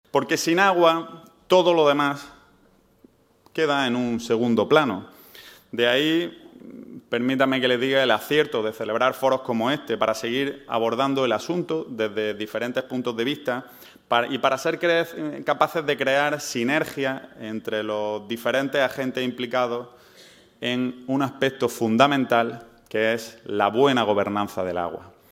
Esta cita que se ha celebrado en el Teatro Apolo de Almería ha sido inaugurada por la alcaldesa de Almería, María del Mar Vázquez, el presidente de Diputación, Javier A. García, así como por el consejero de Agricultura, Pesca, Agua y Desarrollo Rural, Ramón Fernández-Pacheco.